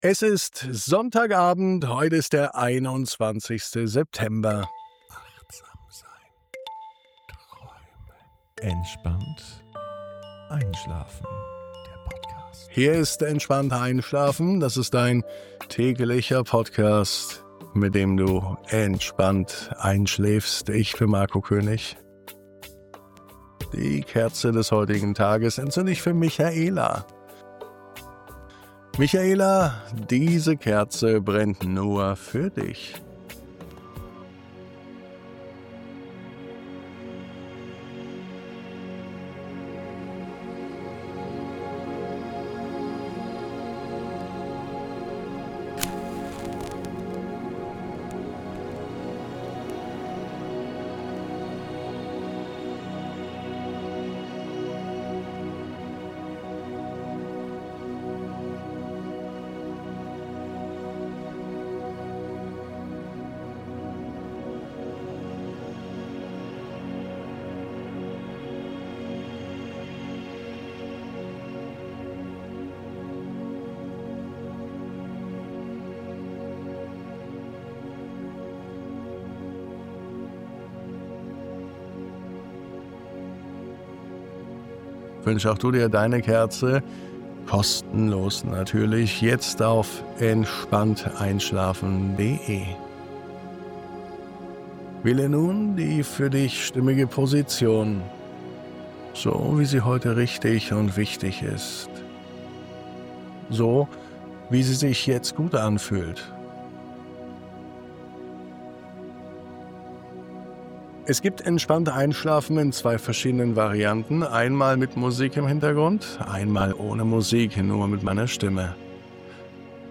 0921_MUSIK.mp3